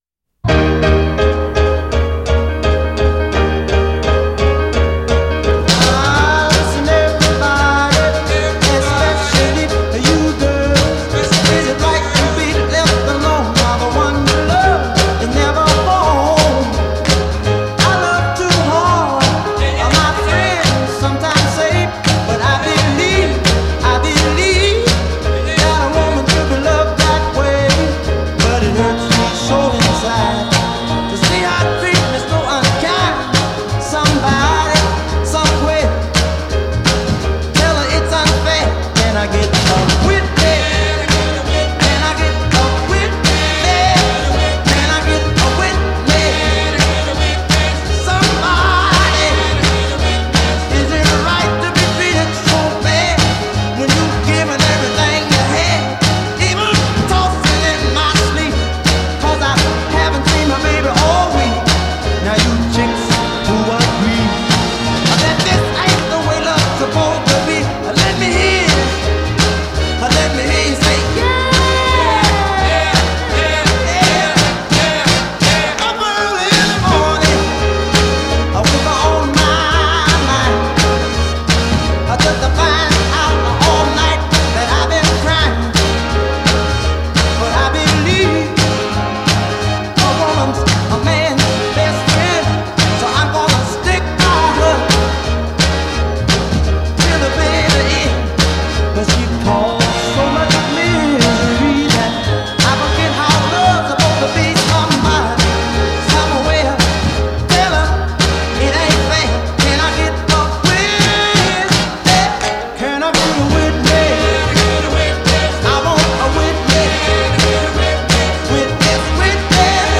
Single Version _ Mono